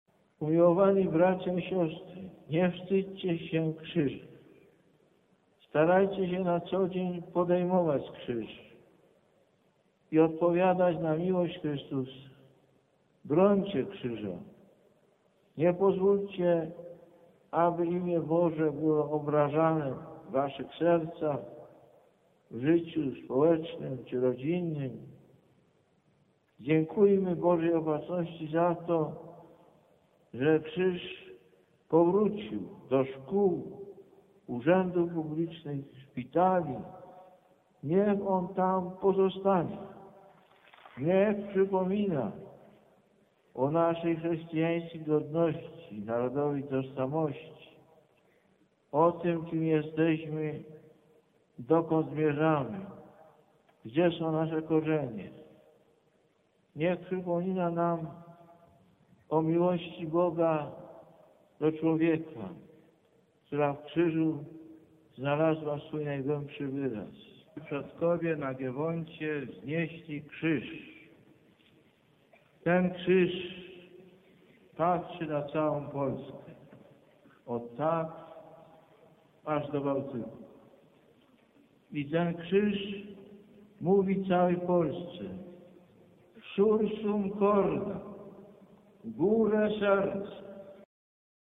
Mówi do nas Św. Jan Paweł II